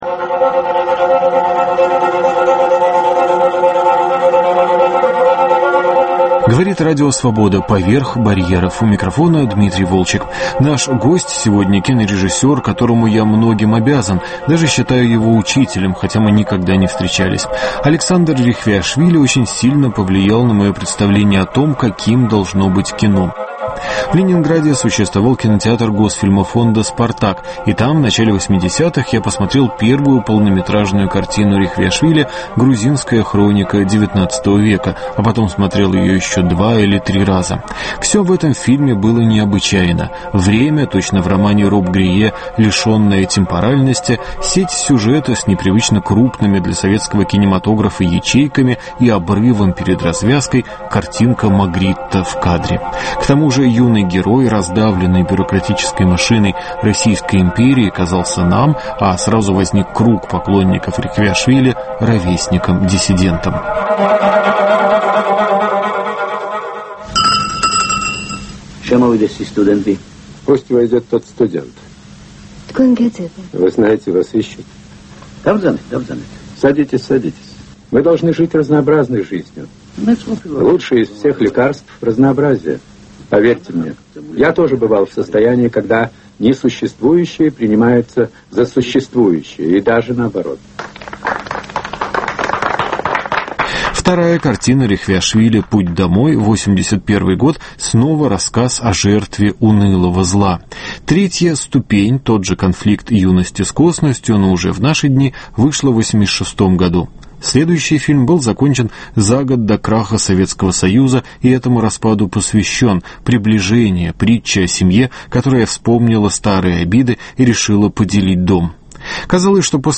Беседа с режиссером Александром Рехвиашвили.